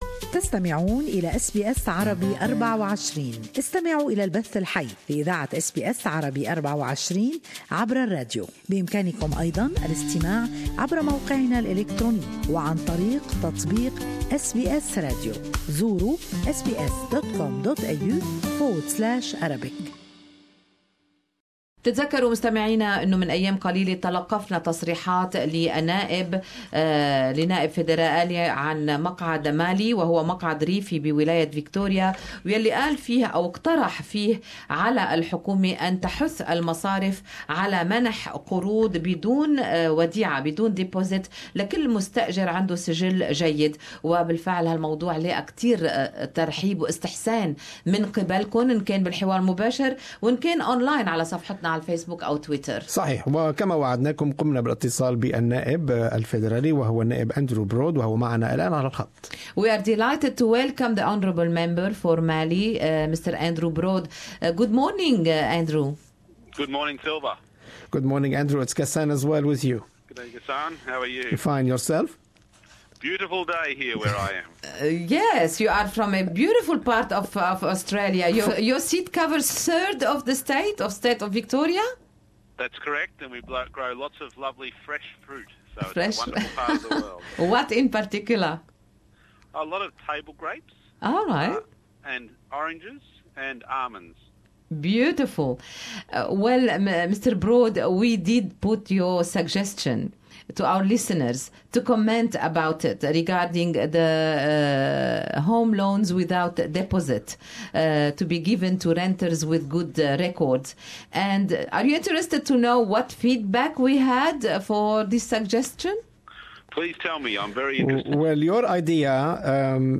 To speak more about this topic, the morning program, Good Morning Australia, on SBS Arabic 24 interviewed MP Broad to see where he got the idea from and whether it will be taken seriously within the government.